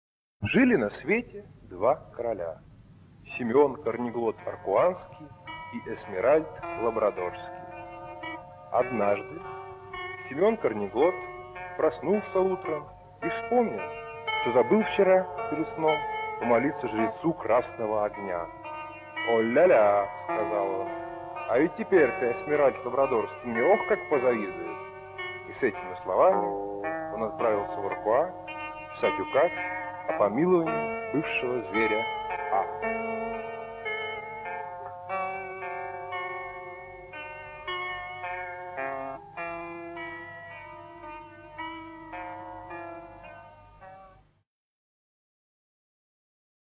Записано в феврале 1973 года в ЛГУ на факультете ПМиПУ
Очень смешно, но очень плохо записано".